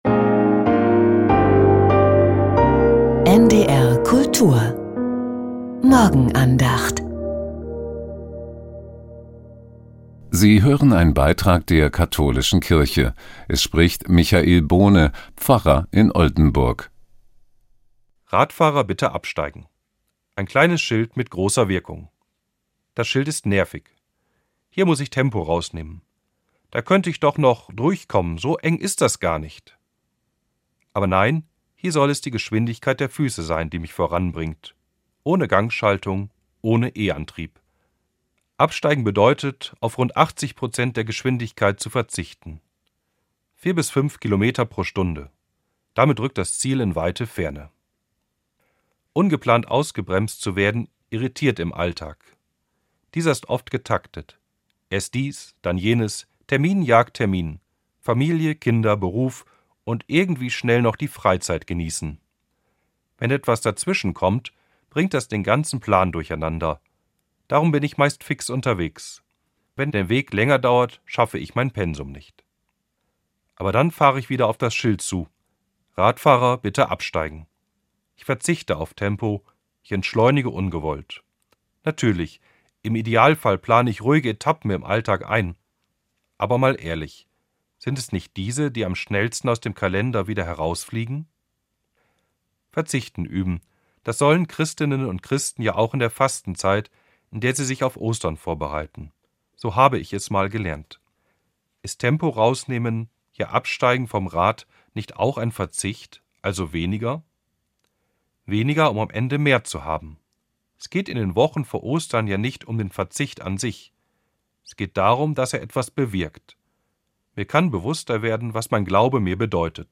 Die Morgenandacht